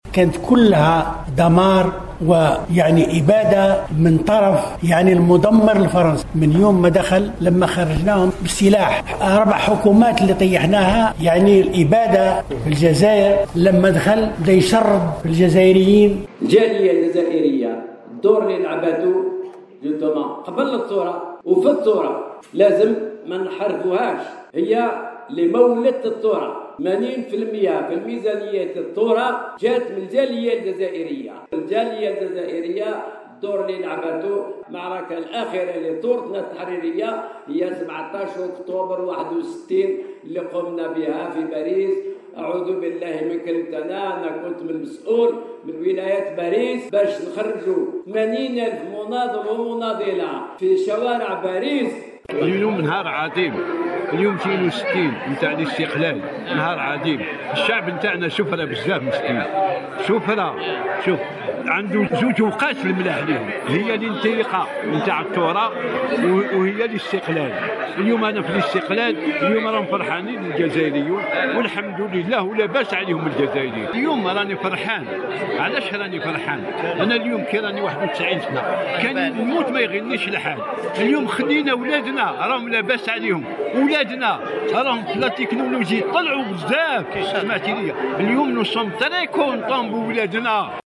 إحتفال جامعة التكوين المتواصل بالذكرى المزدوجة لعيدي الإستقلال و الشباب
شهادات-حية-لمجاهدي-الثورة-التحريرية.mp3